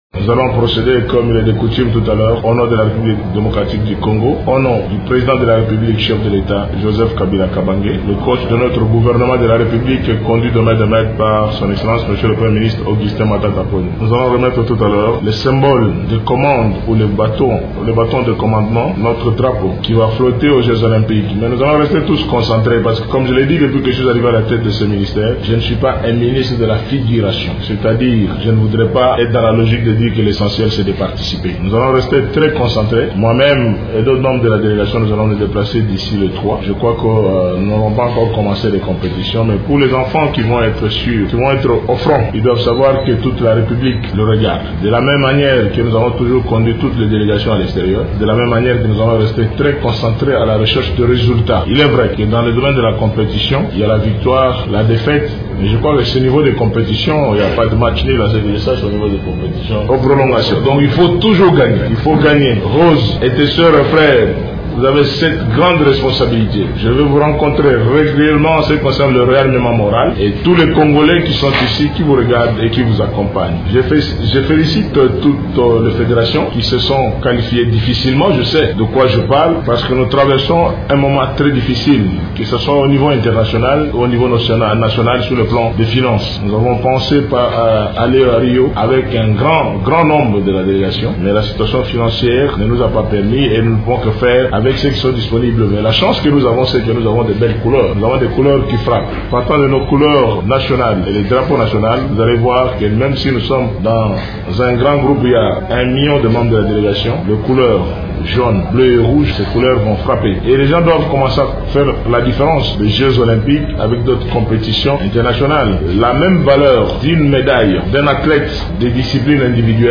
Ecoutez la réaction du ministre: